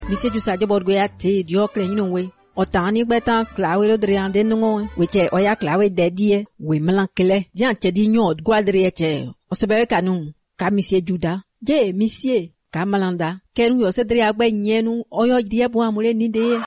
It does sound, however, (perhaps due to the initial music) like the beginning of a news broadcast.